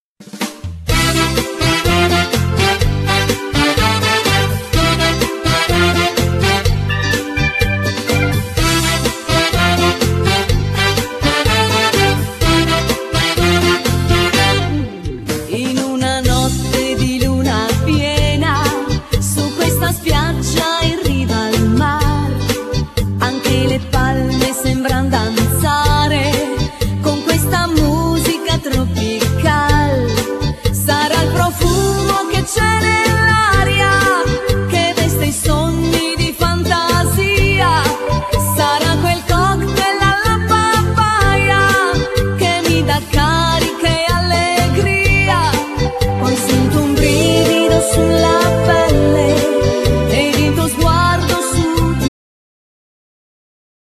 Genere : Liscio folk
chacha